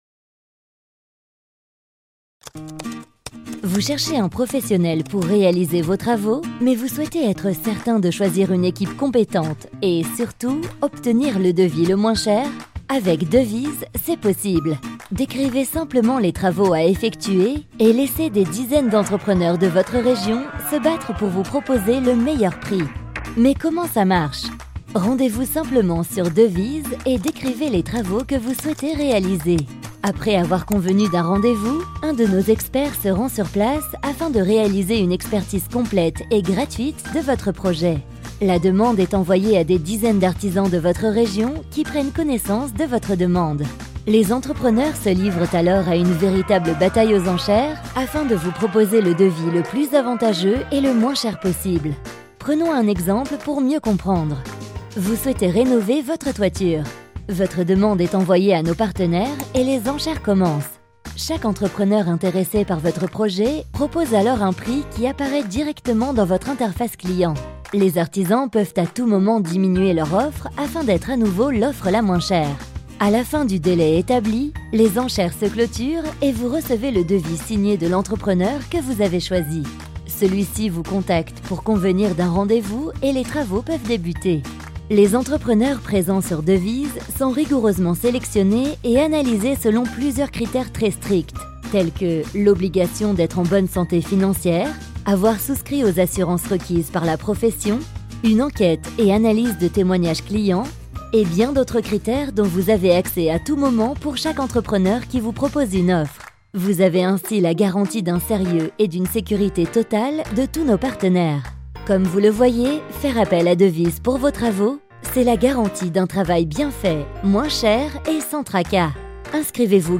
My voice is young and fresh and can match your projects for tv and radio commercials, corporate videos, e-learning, IVR, but also dubbing and voice over for documentary
Sprechprobe: Industrie (Muttersprache):